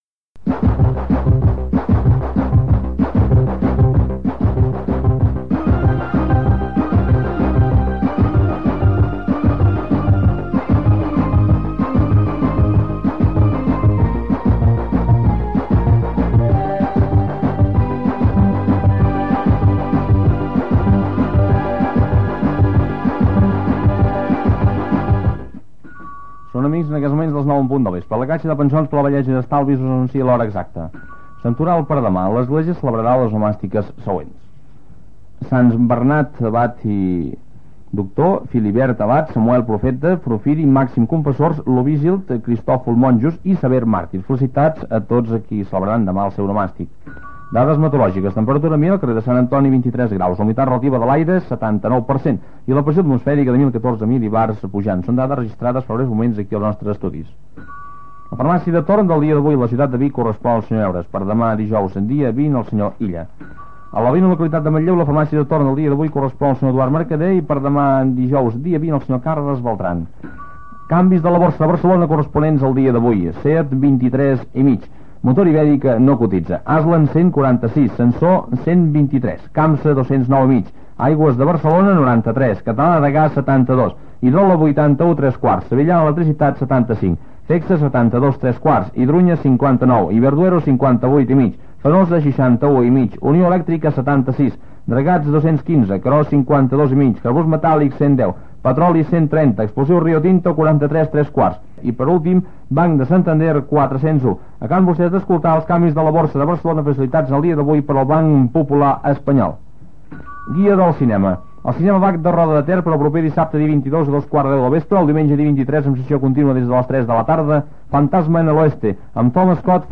Informatiu
FM